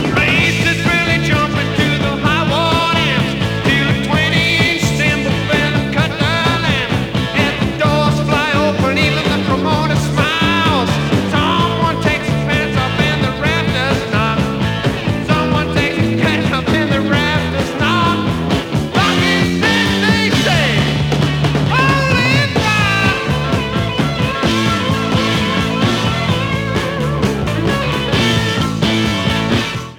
BBC version - Mono